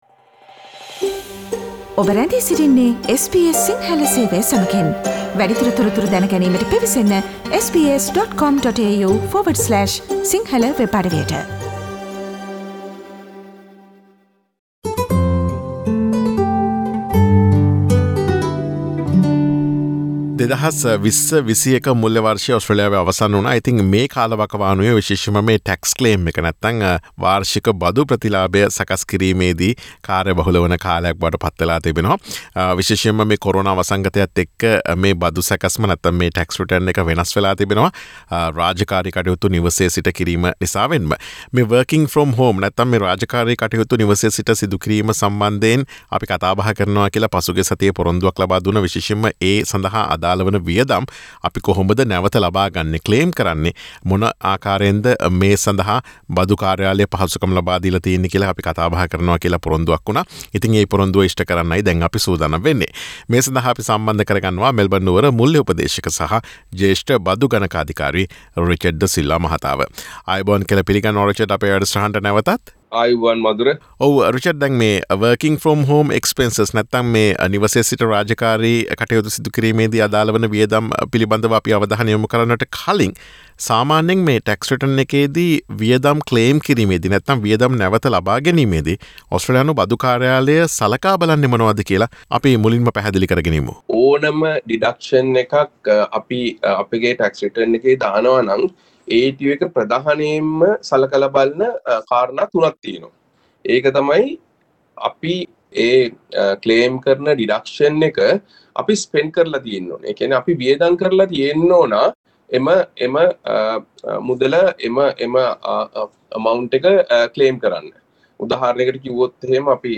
මෙවර Tax Return එකේදි Work from home වියදම් නිවැරදිව claim කරන අයුරු සහ එහිදී ඔස්ට්‍රේලියානු බදු කාර්යාලය සලකා බලන නිර්ණායක පිළිබඳව SBS සිංහල ගුවන් විදුලිය සිදුකළ සාකච්ඡාවට සවන්දෙමු.